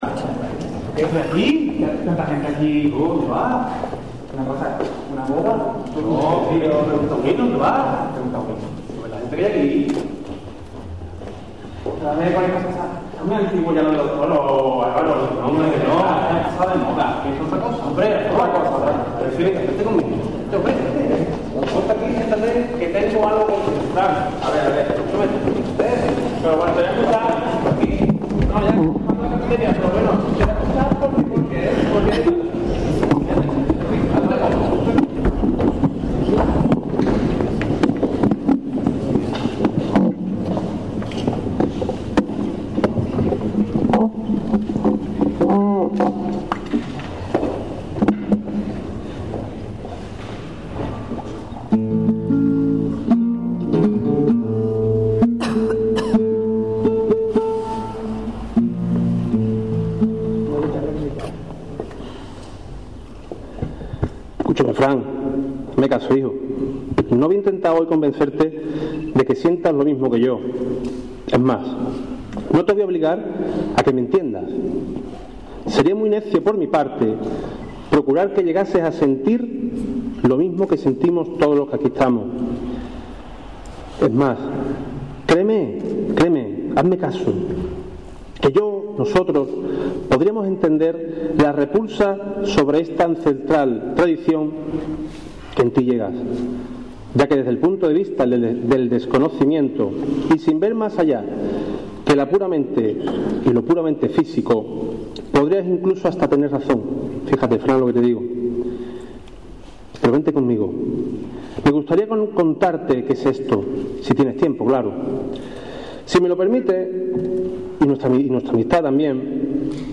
Un pregón reivindicativo.